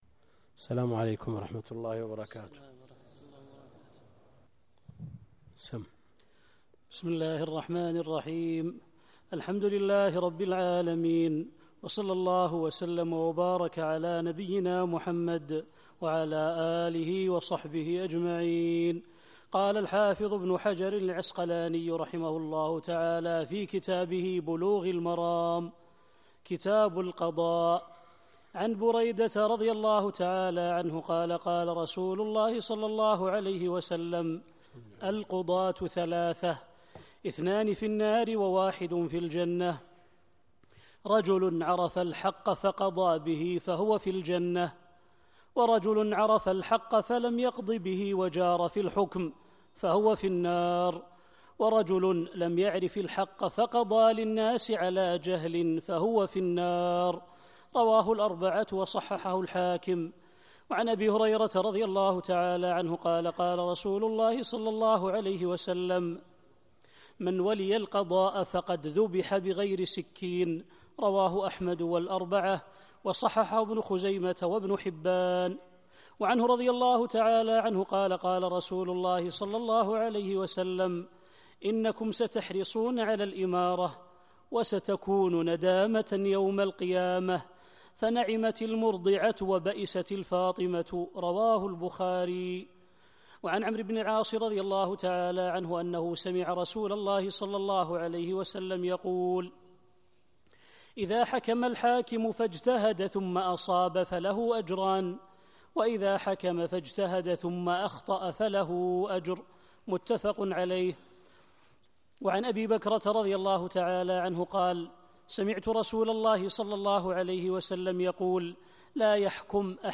الدرس (1) كتاب القضاء من بلوغ المرام - الدكتور عبد الكريم الخضير